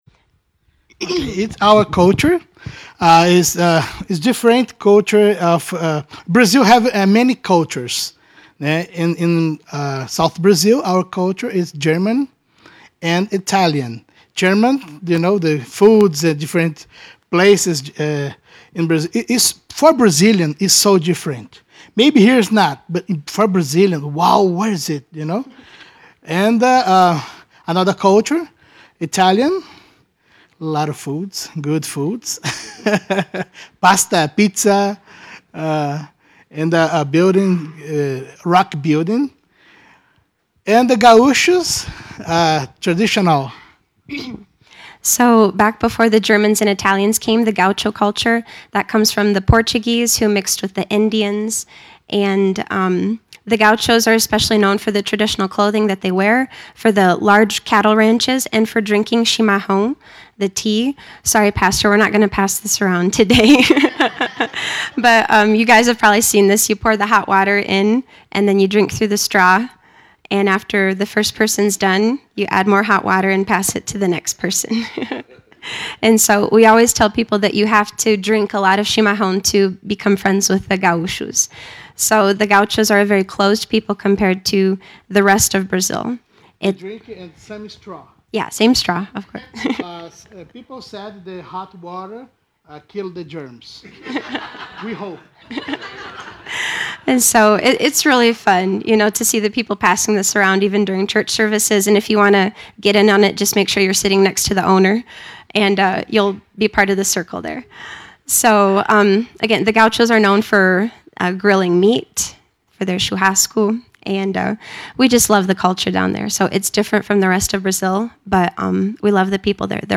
Service Type: Morning Service Brazilian pastor missionary Topics: Brazil , missions